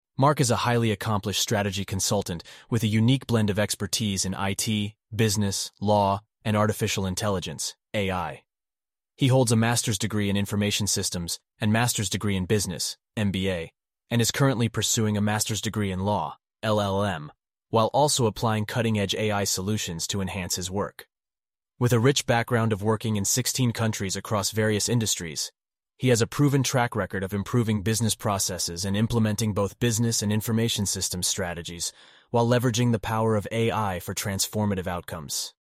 synthesized_audio-2.mp3